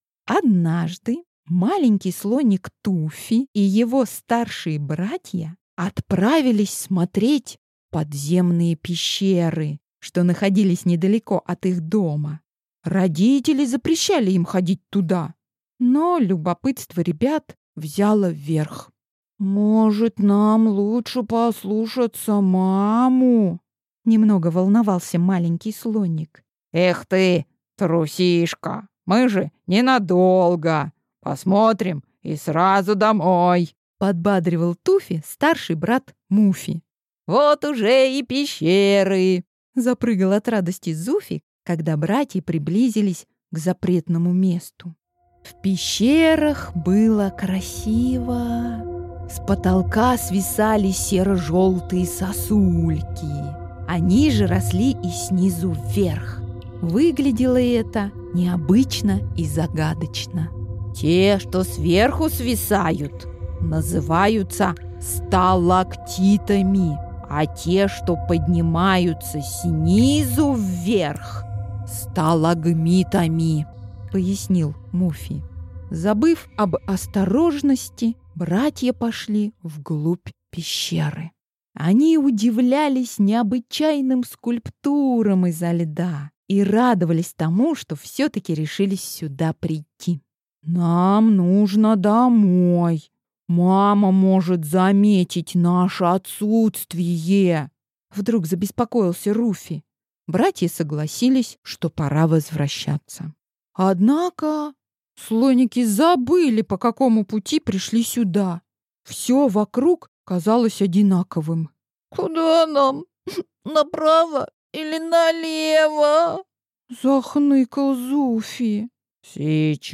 Аудиокнига Сказки для и про особенных детей | Библиотека аудиокниг